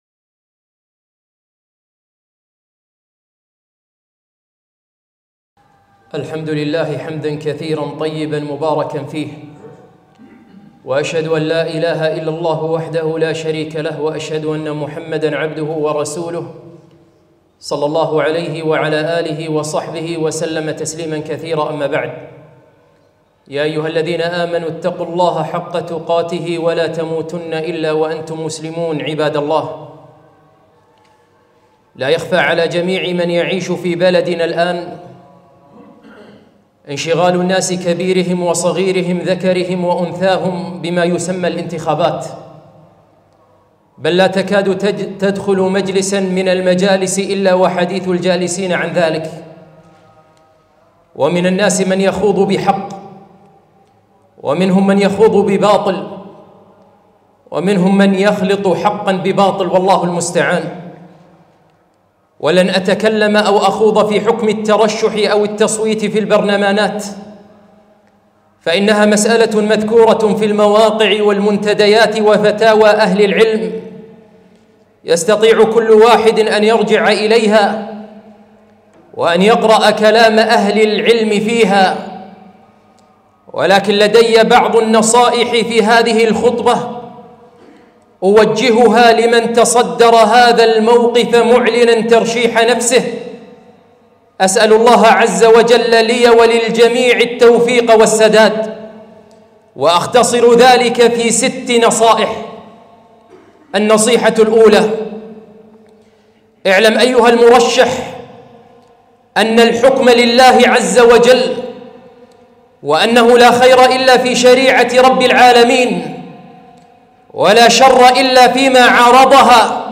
خطبة - ست نصائح لمرشح